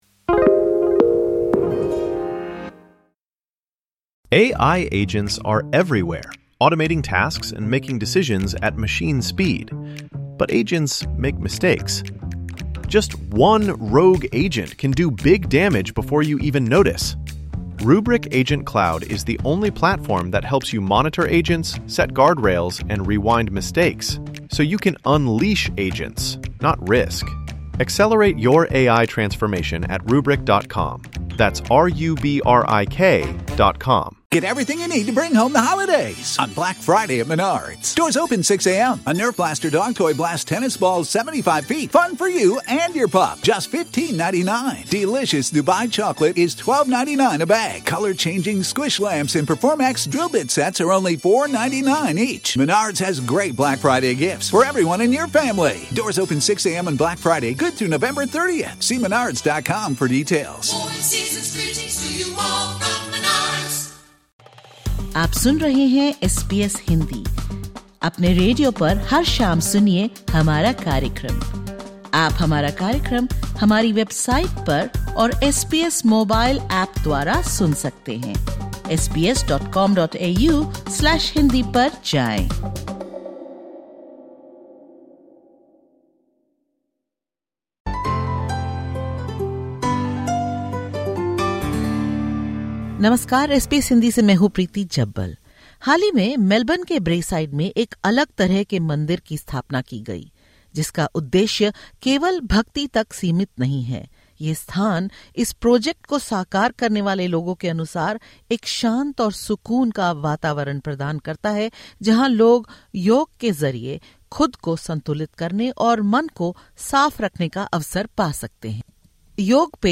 DISCLAIMER: The information expressed in this interview is of general nature.